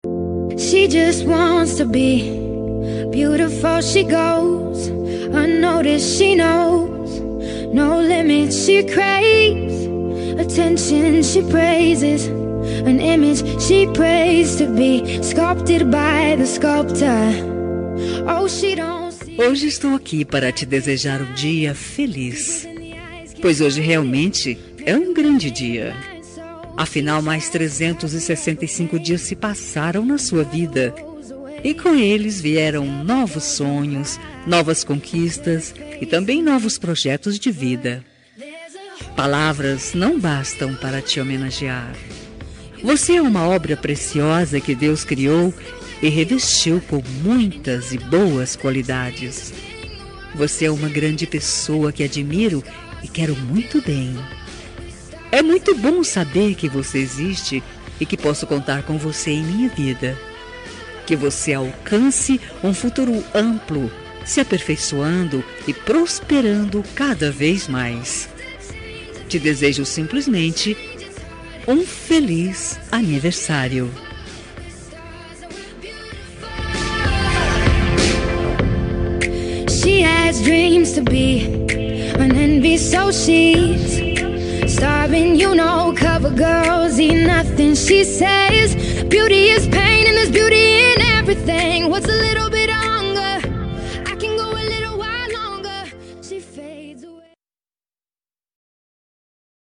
Telemensagem de Aniversário de Pessoa Especial – Voz Feminina – Cód: 202215